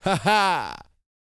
Index of /90_sSampleCDs/ILIO - Vocal Planet VOL-3 - Jazz & FX/Partition I/2 LAUGHS
LAUGH 701.wav